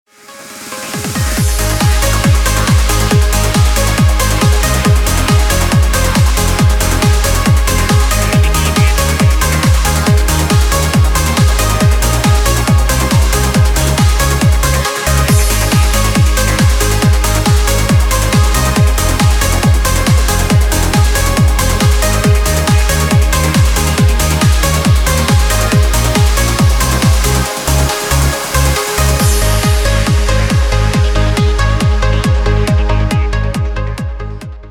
• Качество: 320, Stereo
без слов
club
Trance
Uplifting trance